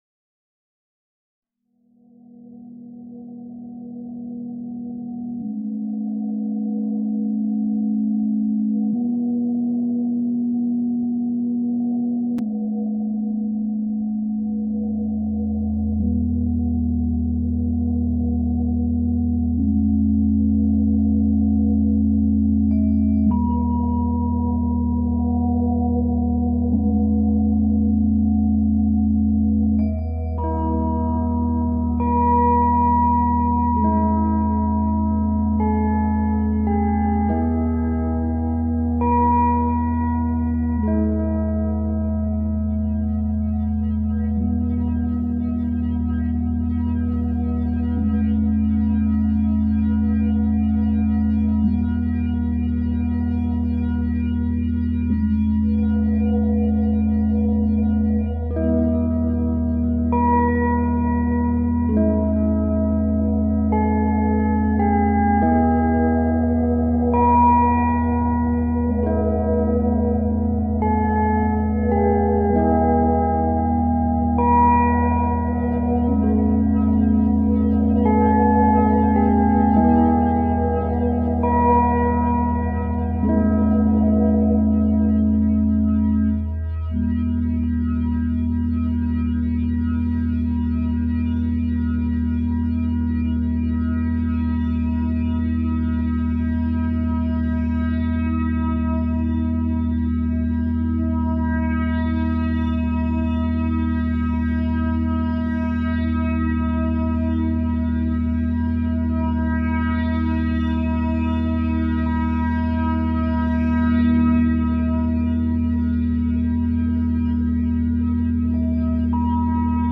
963Hz – فرکانس 963 هرتز
در این فصل می‌خوایم براتون یک سری موسیقی با فرکانس‌های مختلف قرار بدیم که بهشون تون هم می‌گن.
به بعضی از این ها اصطلاحا می‌گن Solfeggio Frequencies که ترجمش فرکانس‌های سلفژی میشه که به فرکانس‌های خاصی اتلاق میشه.
963Hz.mp3